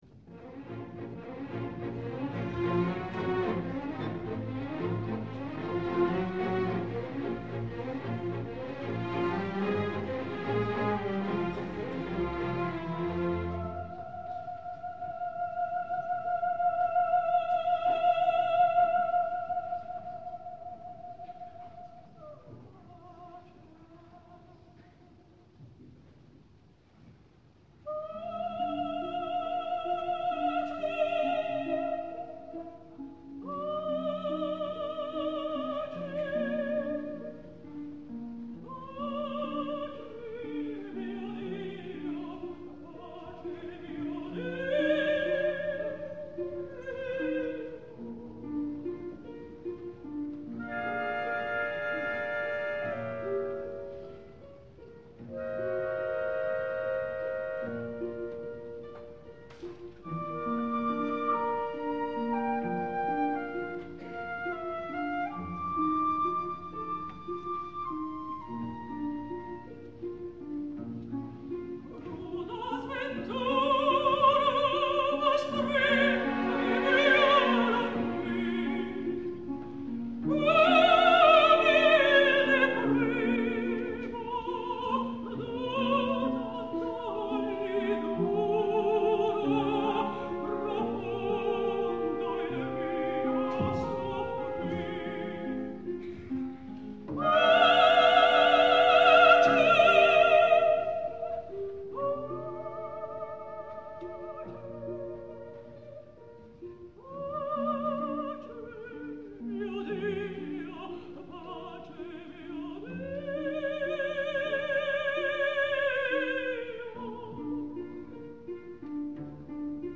opera completa, registrazione dal vivo.